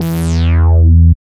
71.09 BASS.wav